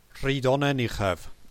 Pour entendre la prononciation de Rhydonnen Uchaf, appuyez sur Play :